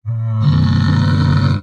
Minecraft Version Minecraft Version 1.21.4 Latest Release | Latest Snapshot 1.21.4 / assets / minecraft / sounds / mob / camel / ambient5.ogg Compare With Compare With Latest Release | Latest Snapshot
ambient5.ogg